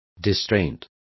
Complete with pronunciation of the translation of distraints.